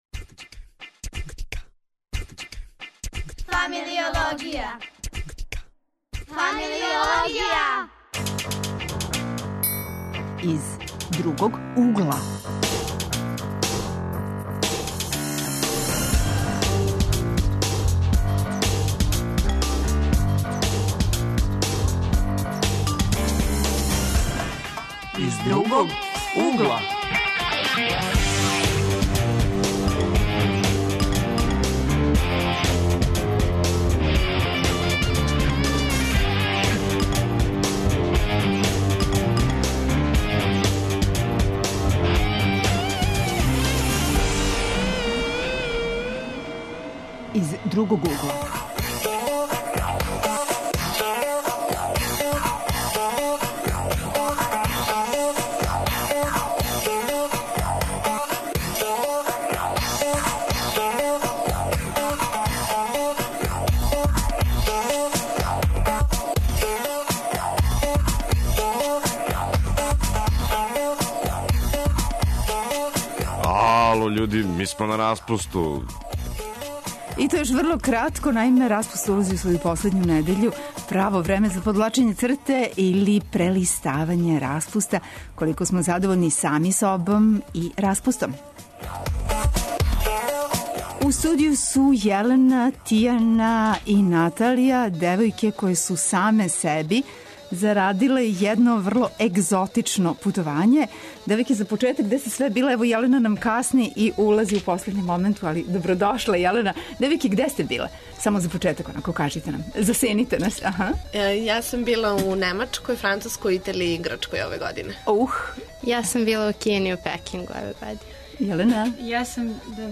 Данас прелиставамо распуст - Из другог угла. Гости су нам млади који су себи обезбедили интересантан, другачији распуст, у Кини, Италији, Грчкој и Немачкој. Чућемо како су то успели и шта је овога лета било у тренду у поменутим земљама.